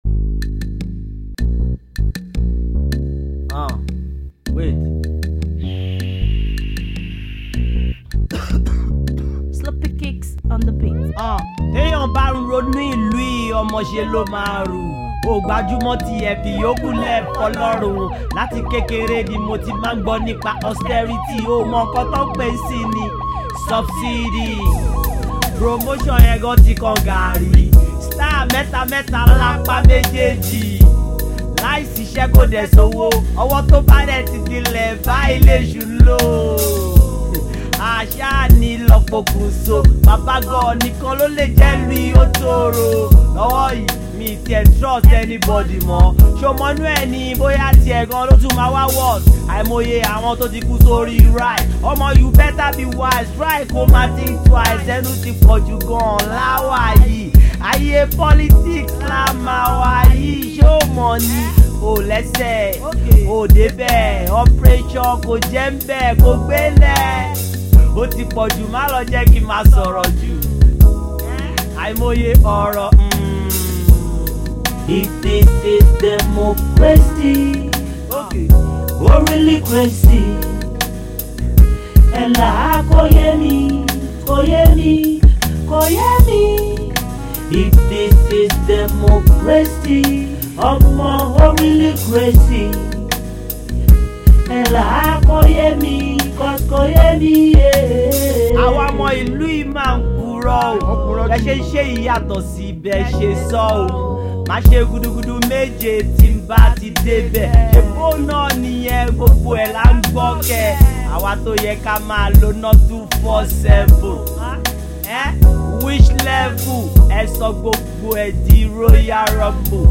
indigenous Hip-Hop, Yoruba Music
Yoruba Hip-Hop